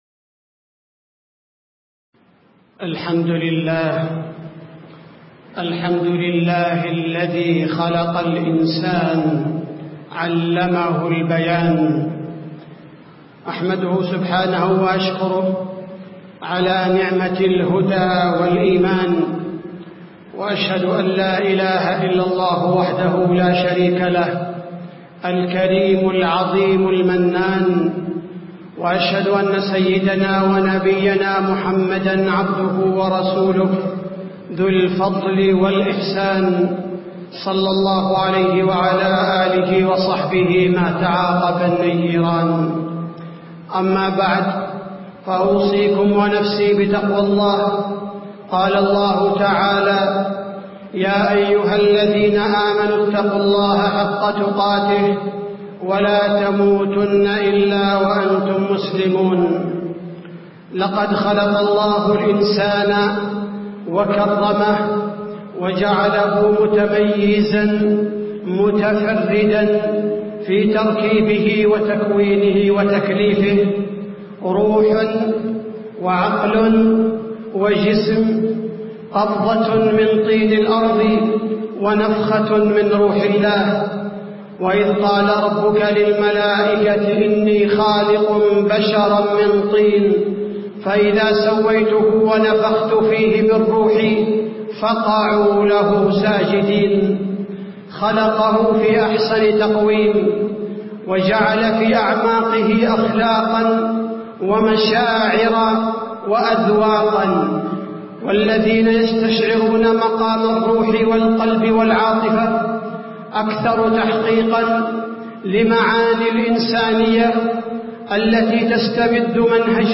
تاريخ النشر ٢٤ محرم ١٤٣٧ هـ المكان: المسجد النبوي الشيخ: فضيلة الشيخ عبدالباري الثبيتي فضيلة الشيخ عبدالباري الثبيتي حماية الإسلام للإنسانية